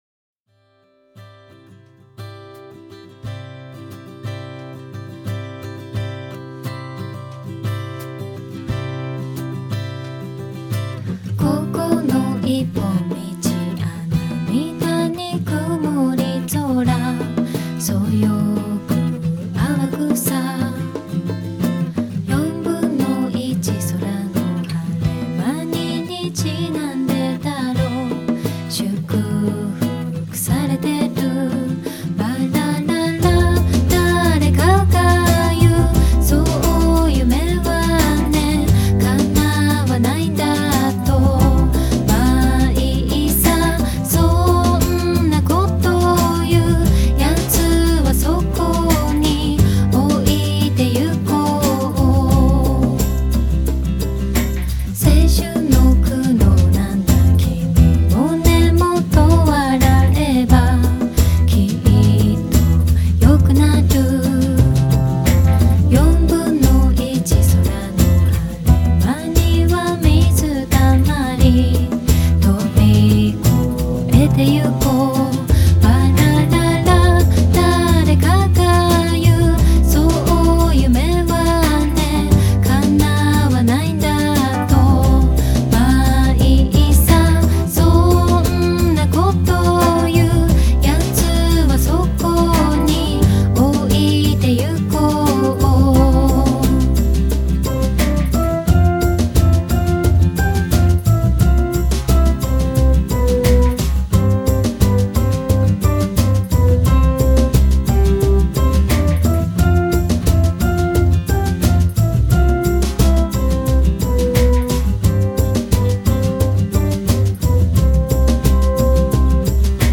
一首很温柔的歌，我想对于很多人来说并不陌生。